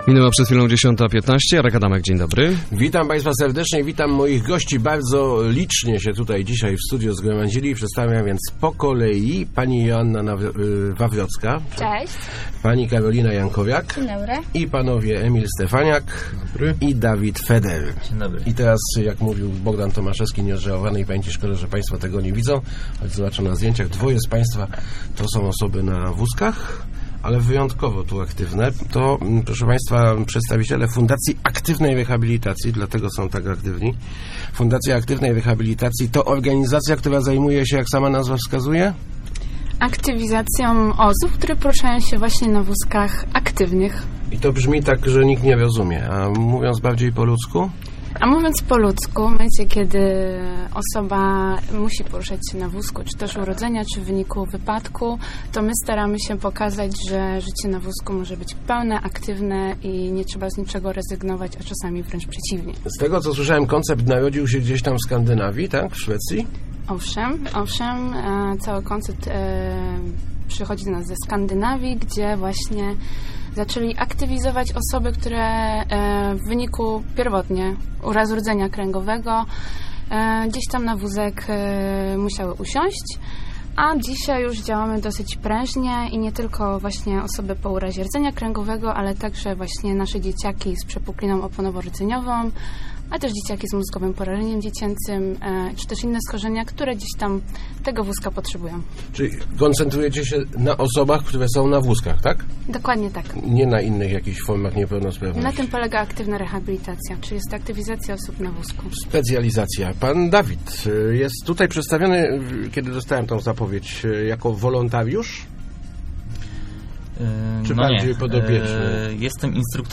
Start arrow Rozmowy Elki arrow Przywrócić niezależność